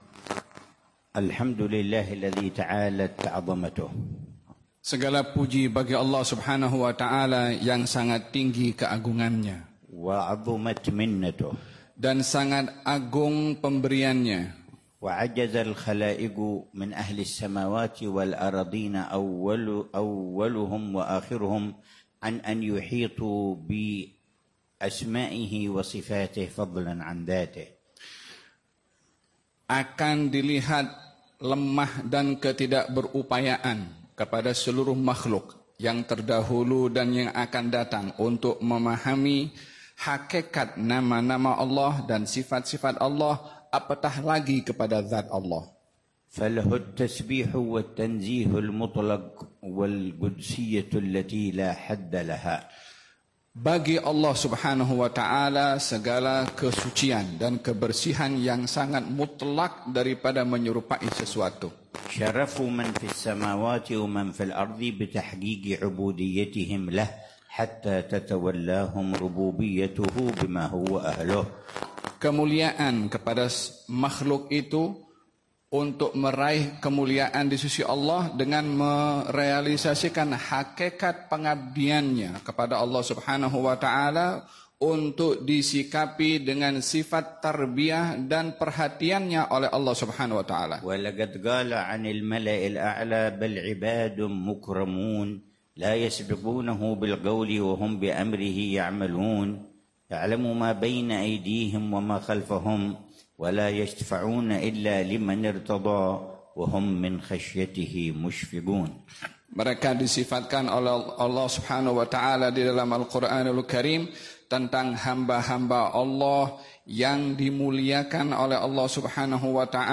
محاضرة العلامة الحبيب عمر بن حفيظ في ملتقى العلماء، في جامعة العلوم والتكنولوجيا مارا (UiTM)، بولاية باهانج، ماليزيا، الأربعاء 23 ربيع الثاني 1447هـ بعنوان: